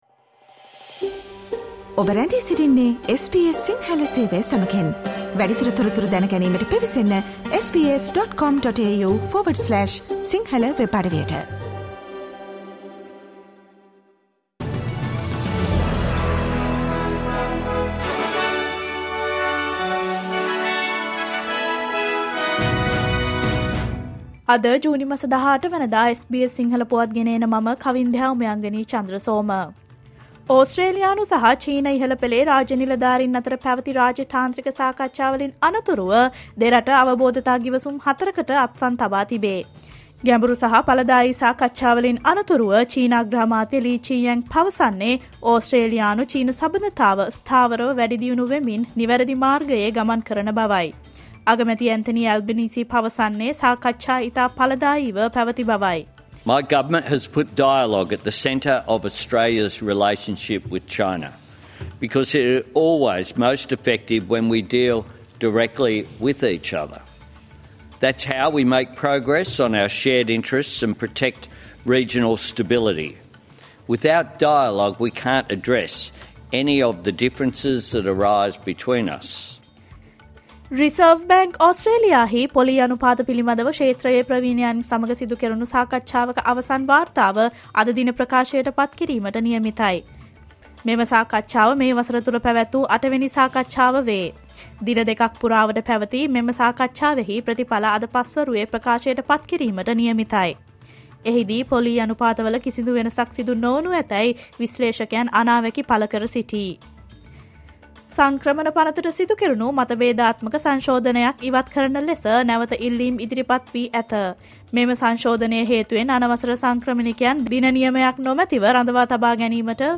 Australia's news in English, foreign and sports news in brief.